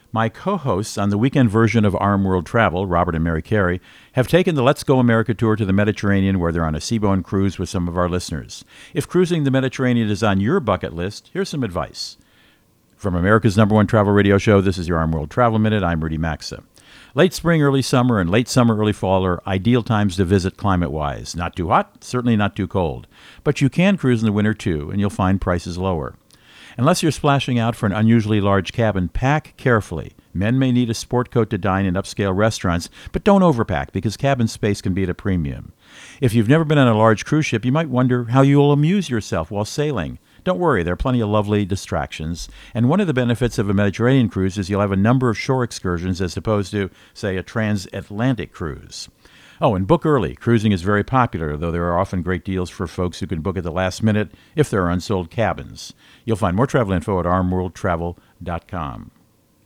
Tuesday, 5 Nov 24 .. Co-Host Rudy Maxa | Cruising in the Mediterranean